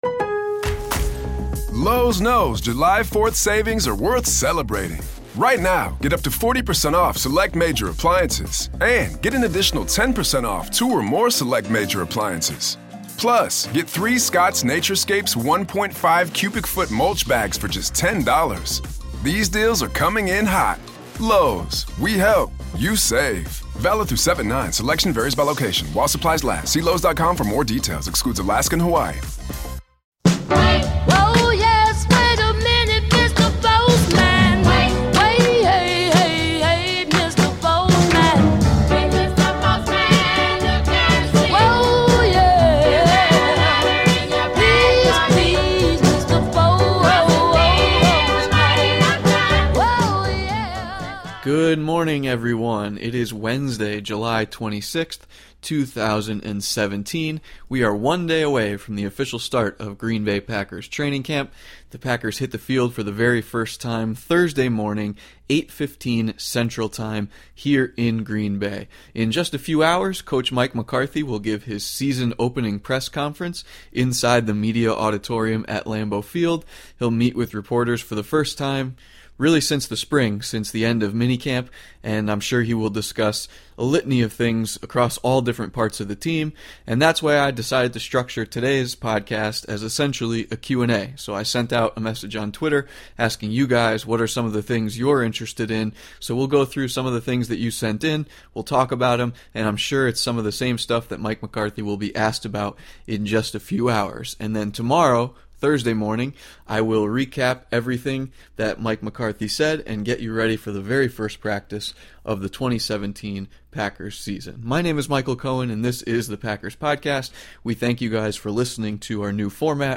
Q&A with listeners